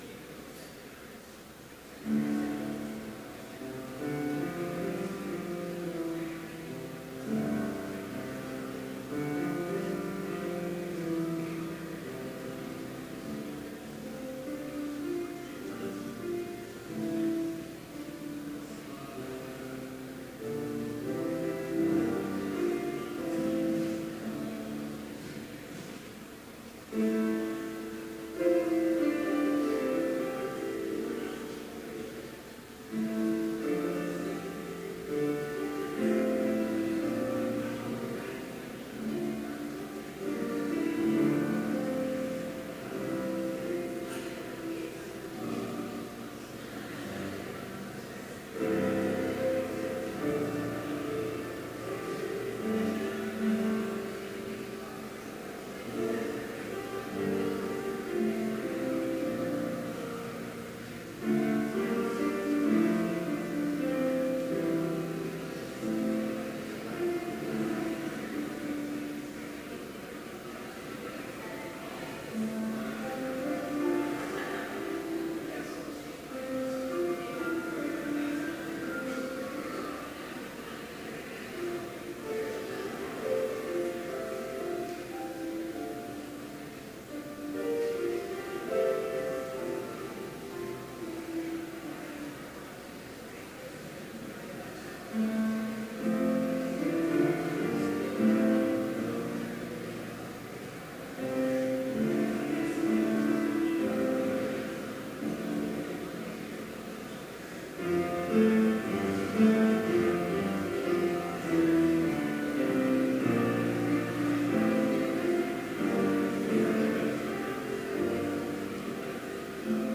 Complete service audio for Chapel - April 6, 2018